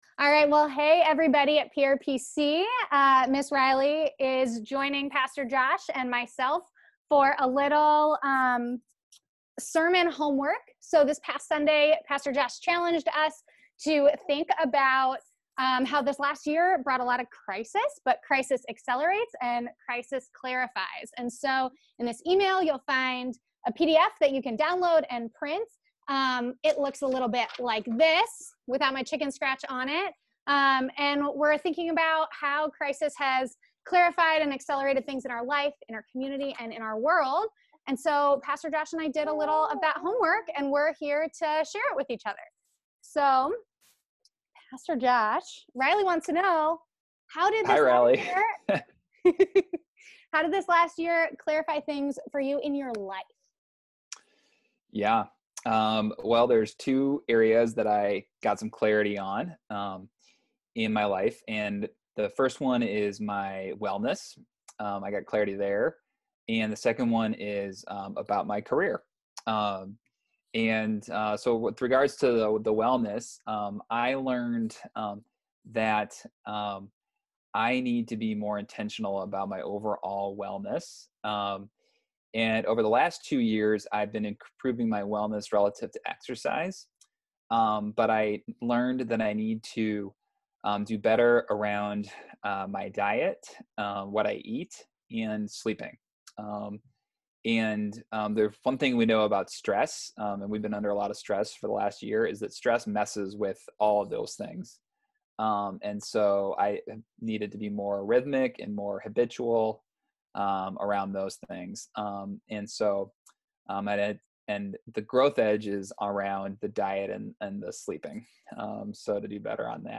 a special conversation